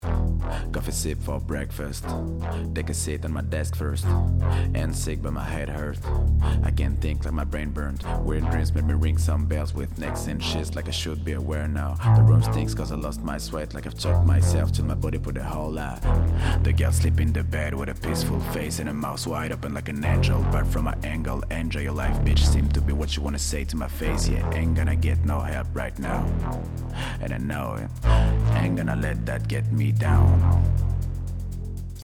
Extrait de single
20 - 35 ans - Ténor